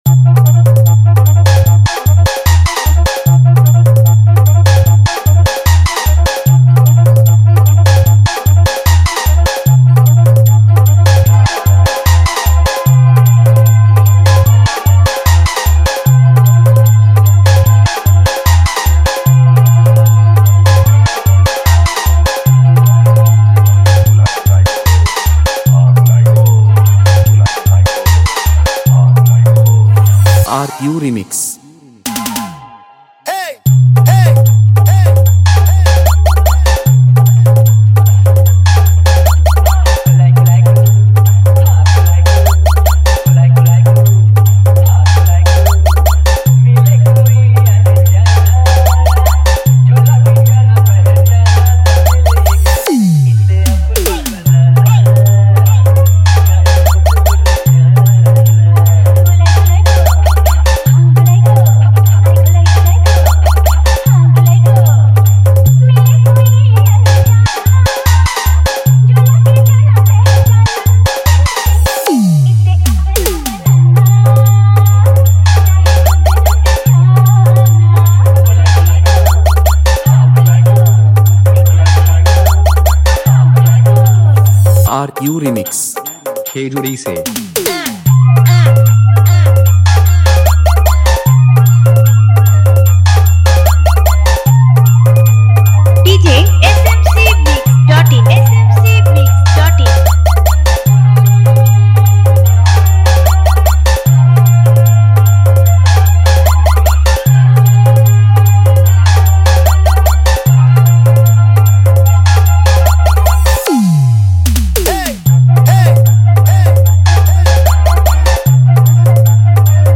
1step Long Pop Bass Humming Mix